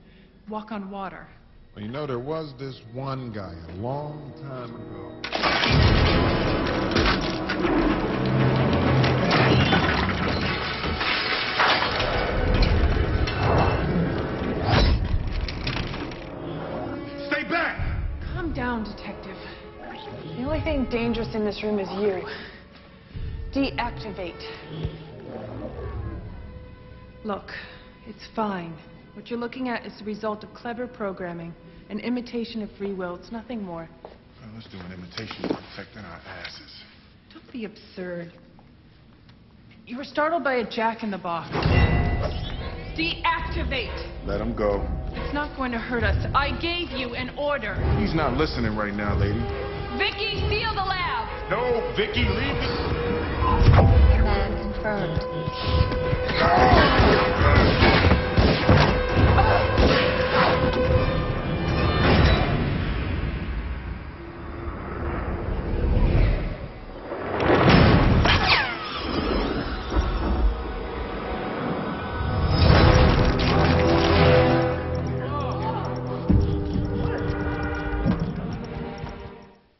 According to mediainfo: 48k, 2ch, 16bit
2. quality was very good to my ears.
demo aac audio, below.